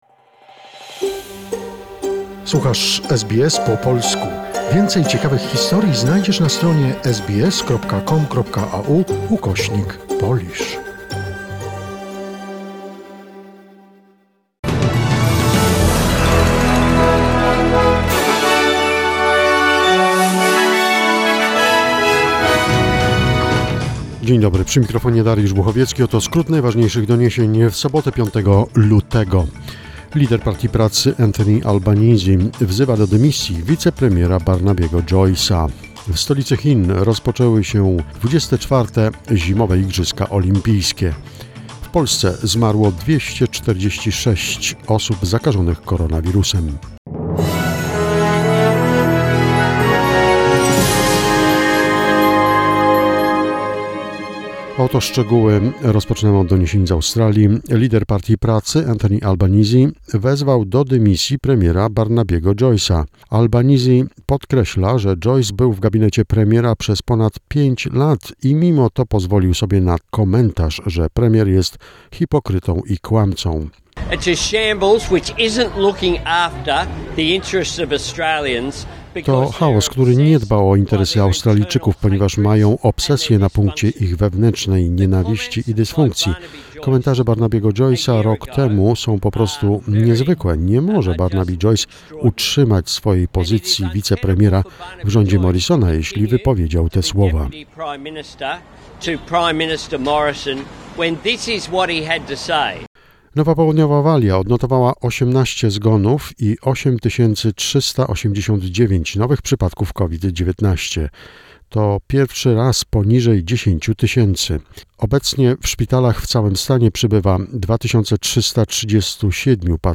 SBS News Flash in Polish, 5 February 2021